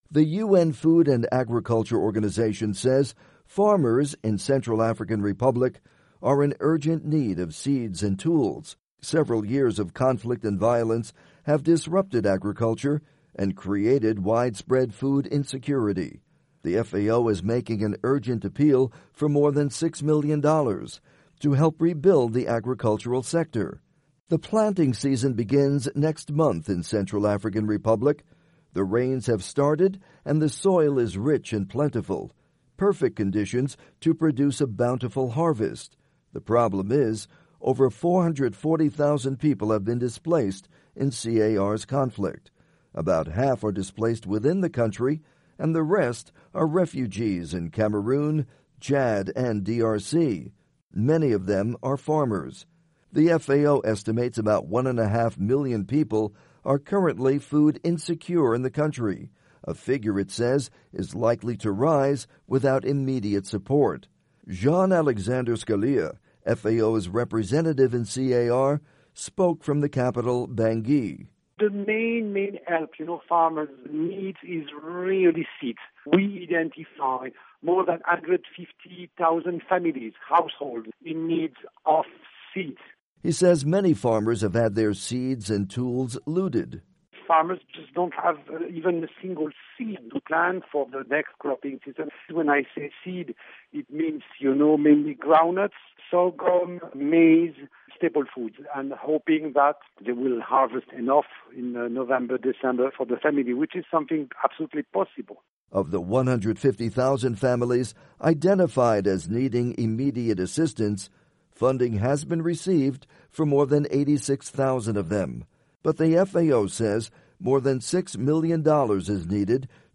report on agriculture in CAR